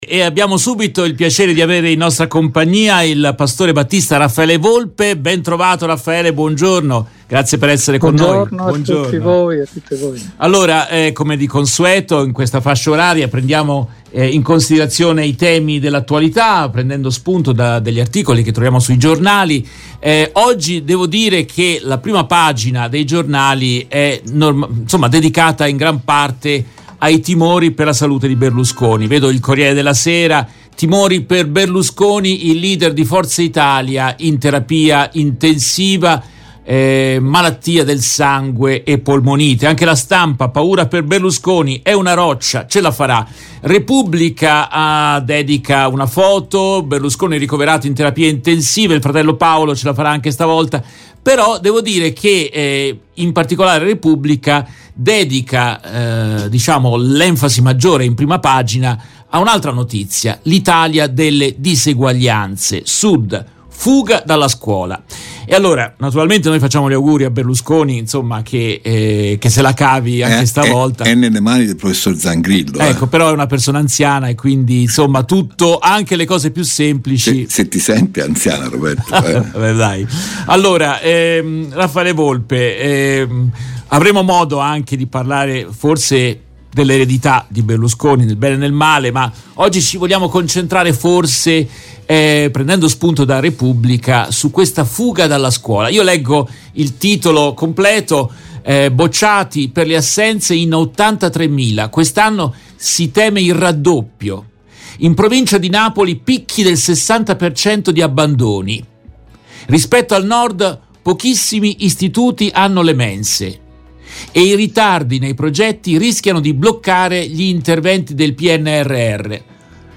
In questa intervista